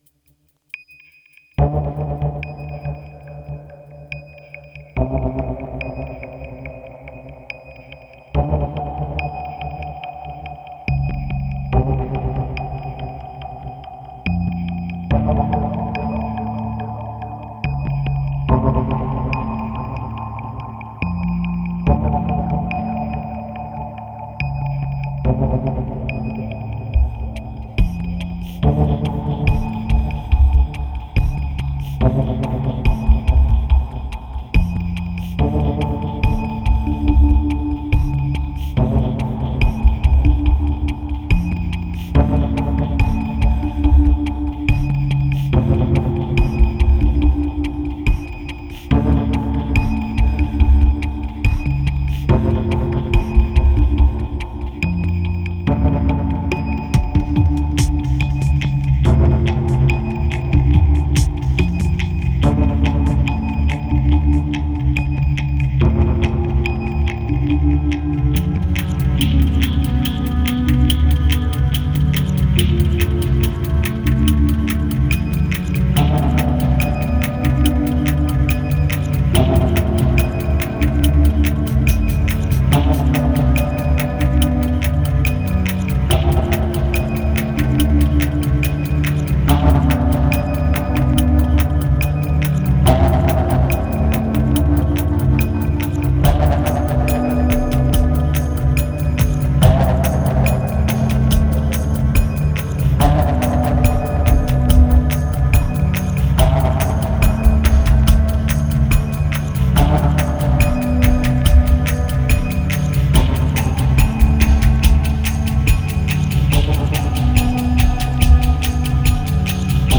1774📈 - 25%🤔 - 71BPM🔊 - 2013-06-29📅 - -24🌟